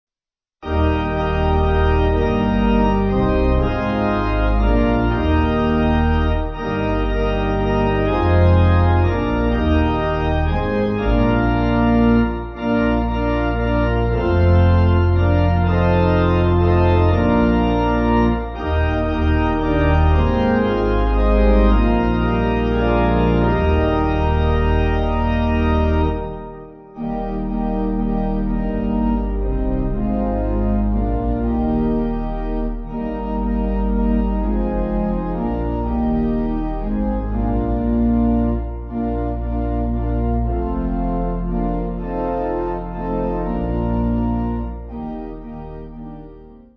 Organ
(CM)   6/Eb